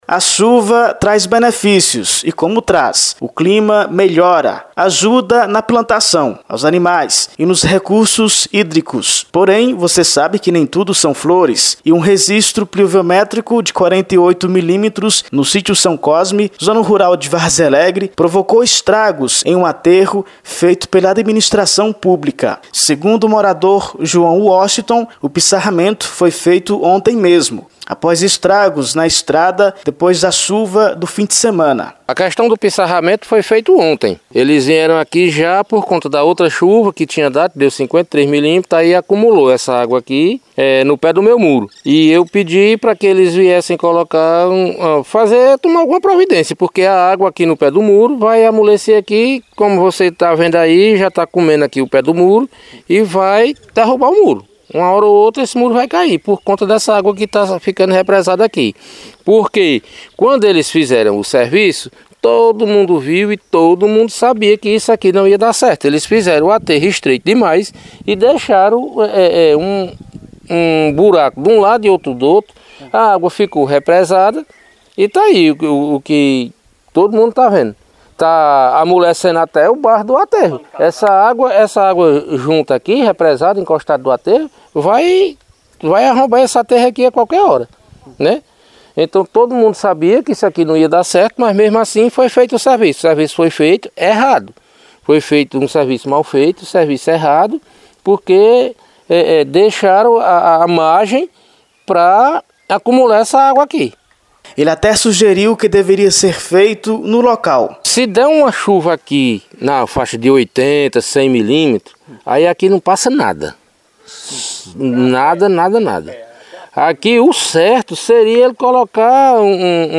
Confira a matéria de áudio: Depois do áudio pronto: Em resposta, a secretaria confirmou o trabalho feito no sítio e disse que o mais breve possível resolverá o problema.